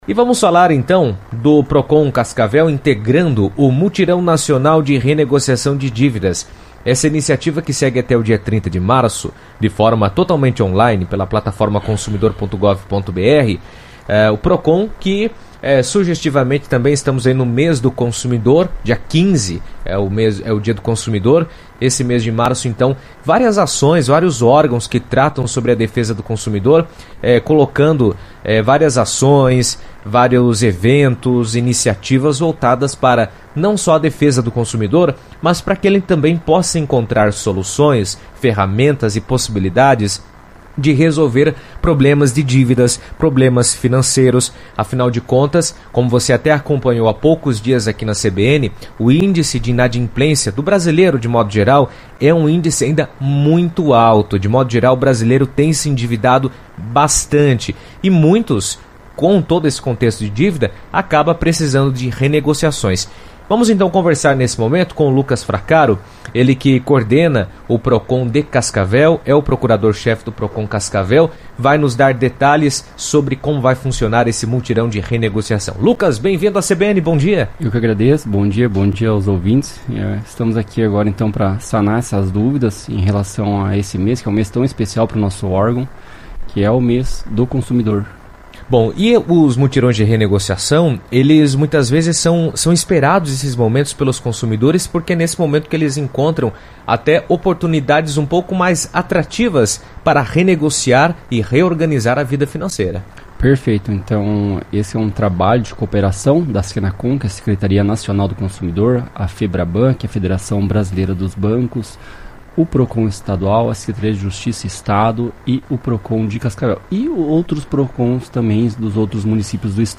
O Procon participa do Mutirão Nacional de Renegociação de Dívidas, iniciativa que busca facilitar acordos entre consumidores e empresas para o pagamento de débitos em atraso. Em entrevista à CBN, o procurador-chefe do Procon Cascavel, Lucas Fracaro, explicou como funciona a ação, quais dívidas podem ser negociadas e de que forma os consumidores podem participar do mutirão.